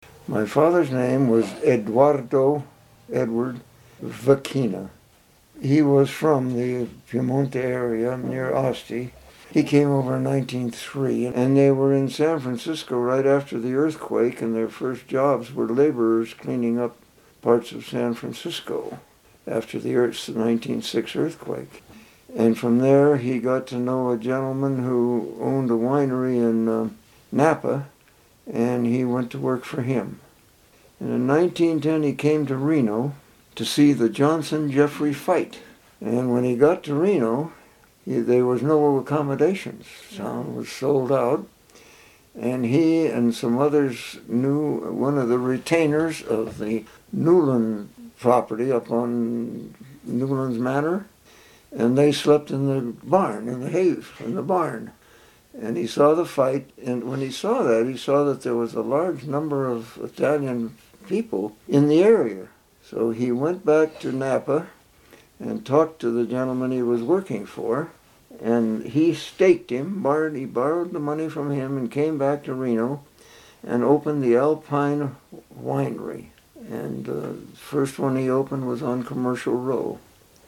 University of Nevada Oral History Program